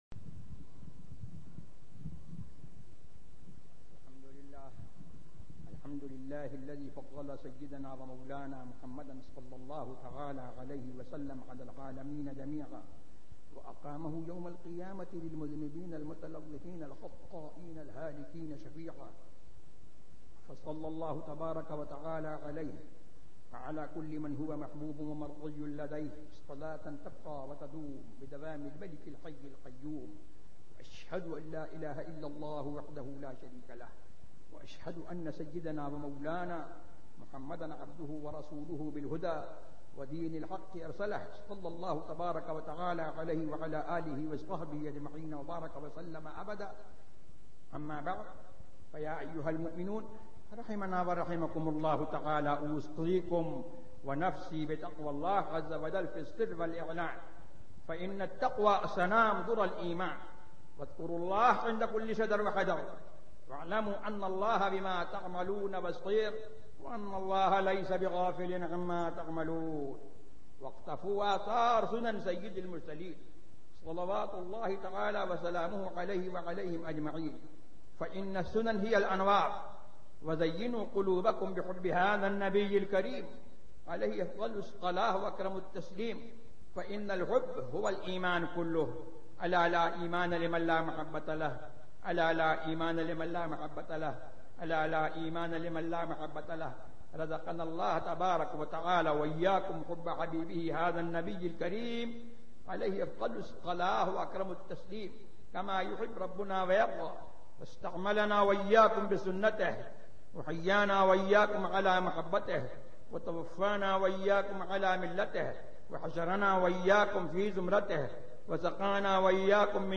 خطبہ کھتری مسجد
تقاریر